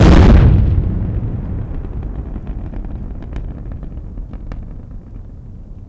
Index of /90_sSampleCDs/AKAI S6000 CD-ROM - Volume 1/SOUND_EFFECT/EXPLOSIONS